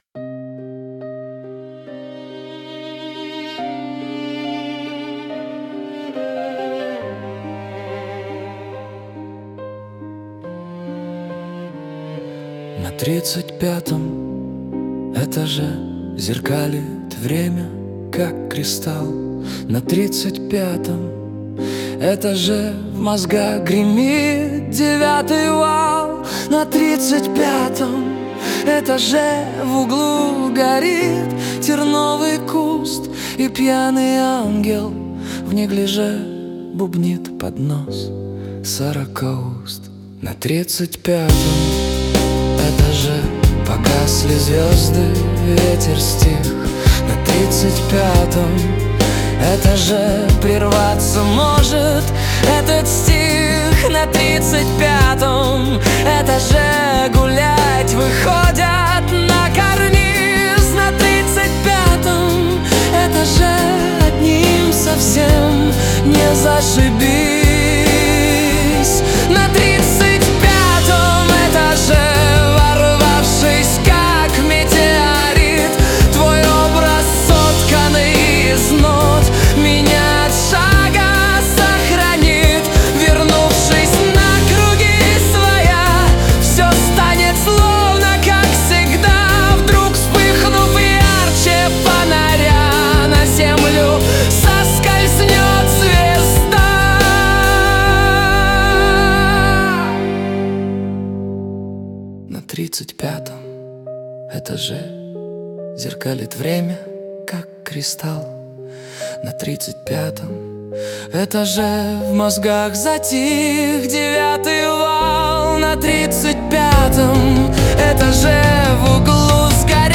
1) Симфо-индипоп
инди-поп с симфоническим слоем, средний темп, лёгкий упругий ритм, живая бас-гитара, воздушные синтезаторы, струнные в припеве, постепенное наращивание к кульминации, эмоциональная но сдержанная подача